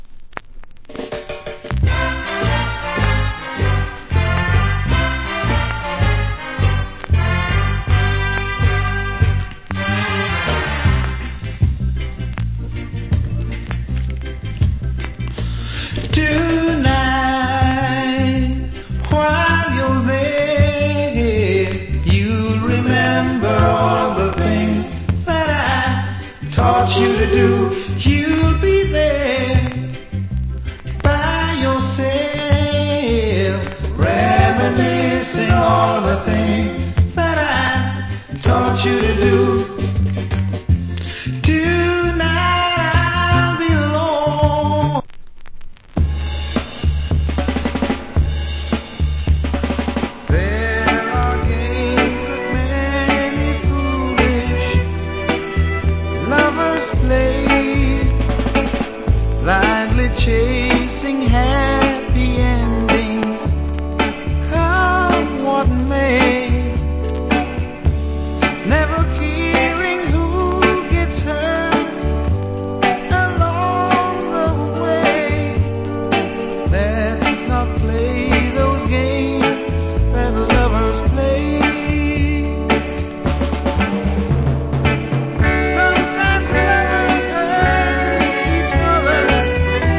Nice Vocal.